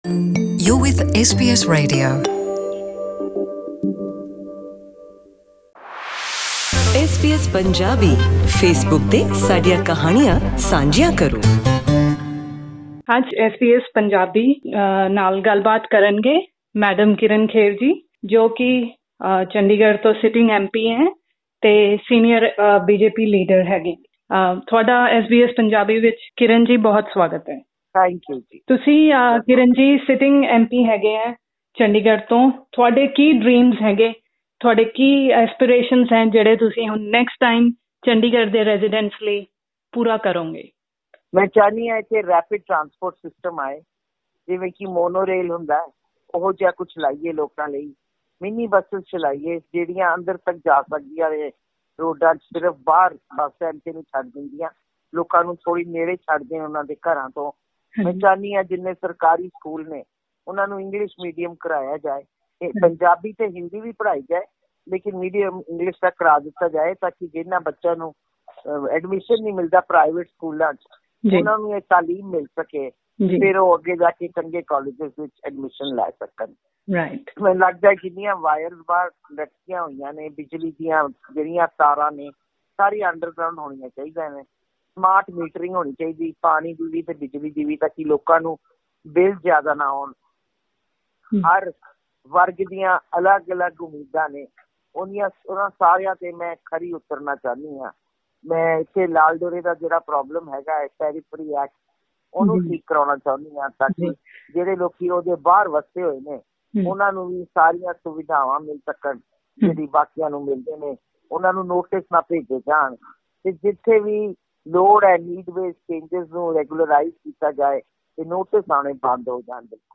The actor-turned-politician speaks about her journey so far as a parliamentarian and the road ahead in an interview with SBS Punjabi.